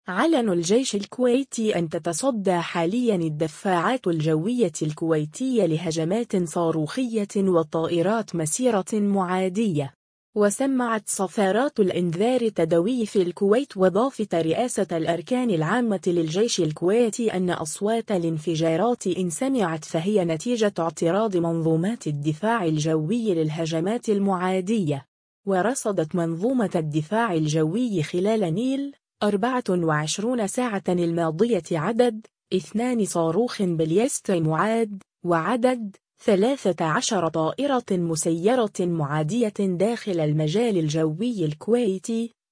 صفارات الإنذار تدوي في الكويت